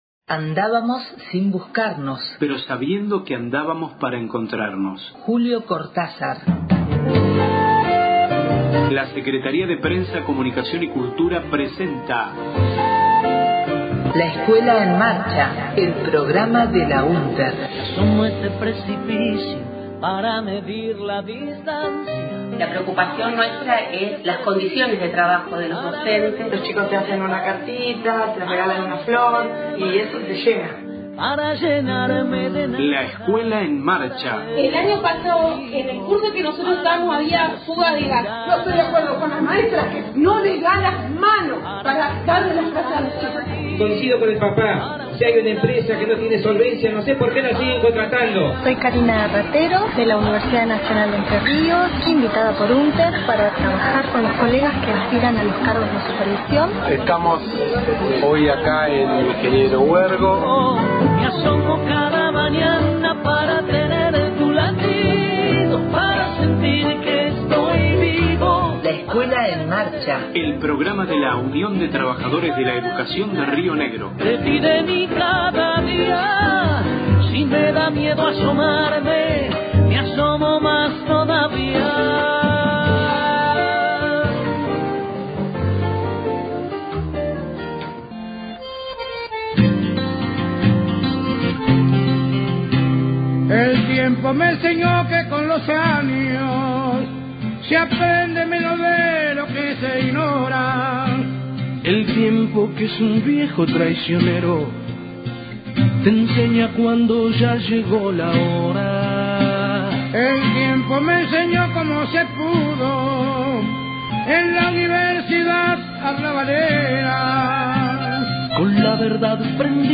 LEEM, radio 14/11/16.